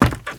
High Quality Footsteps
STEPS Wood, Creaky, Run 16.wav